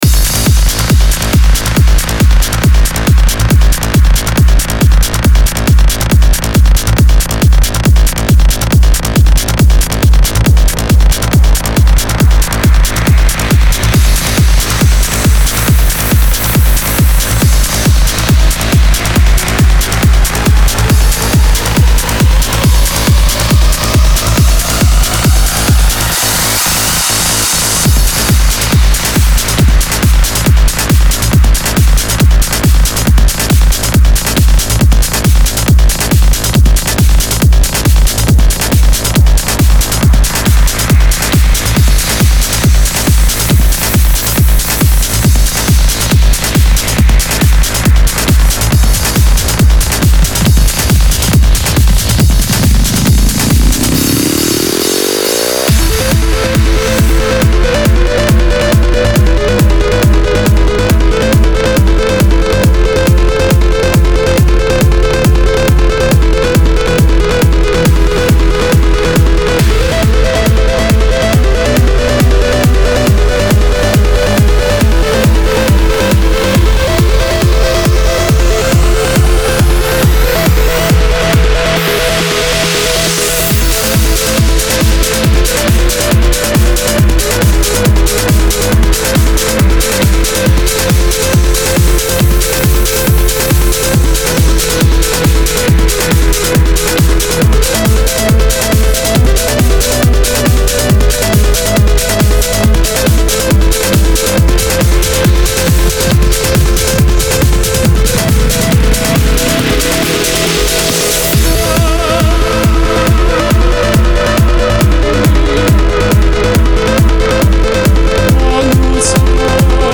Genre: House , Trance , Spacesynth , Synthpop , Electronic.